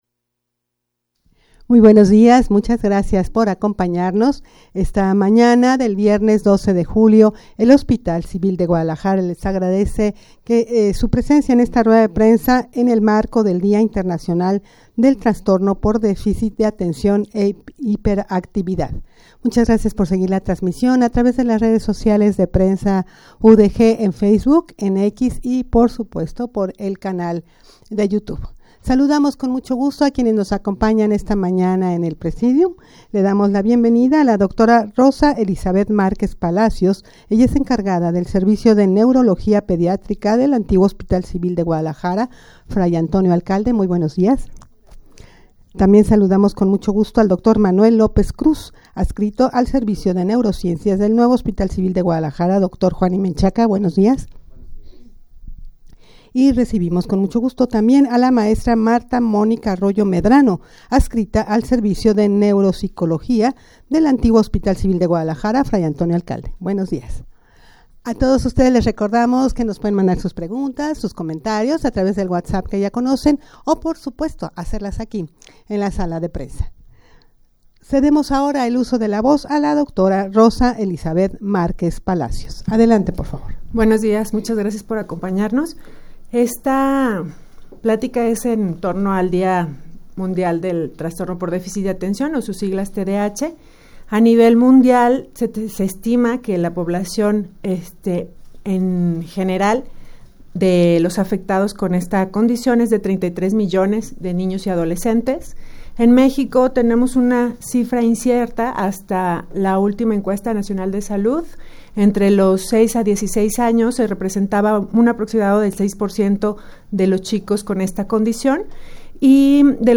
Audio de la Rueda de Prensa
rueda-de-prensa-en-el-marco-del-dia-internacional-del-trastorno-por-deficit-de-atencion-e-hiperactividad-tdah.mp3